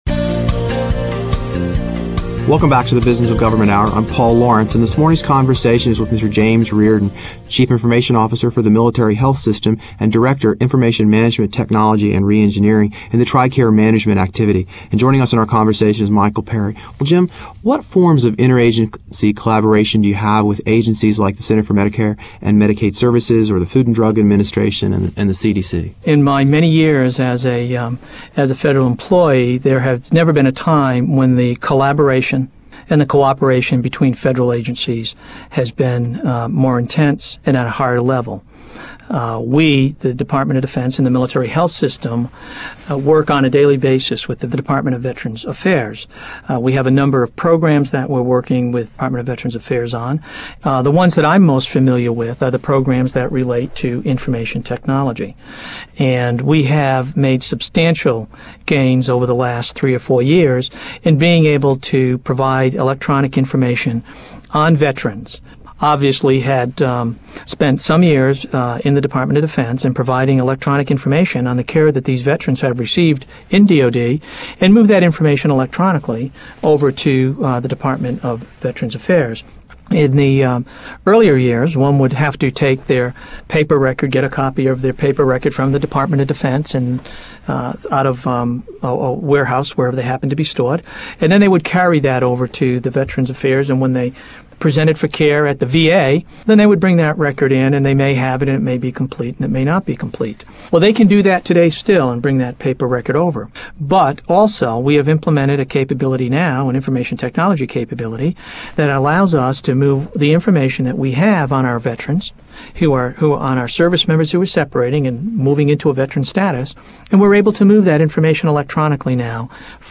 Interviews | IBM Center for The Business of Government